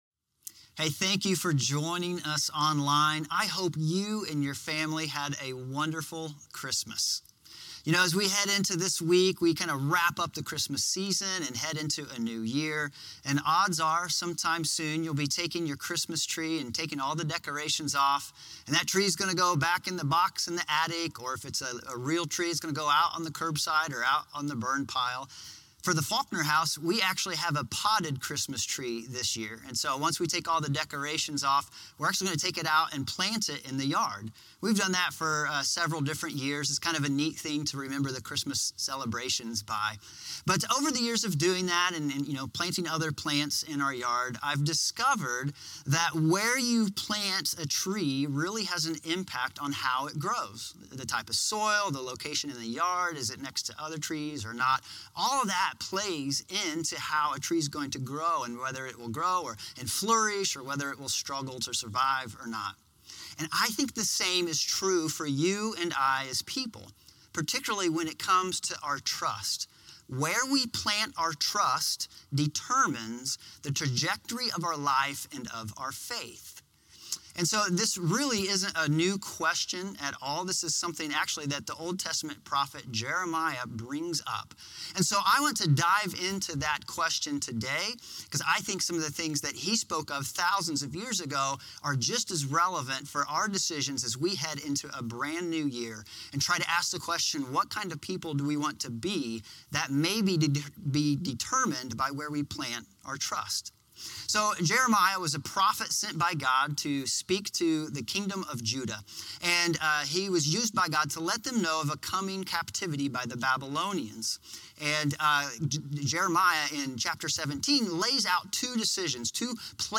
Crossroads Community Church - Audio Sermons 2021-12-26 - Where is my trust planted?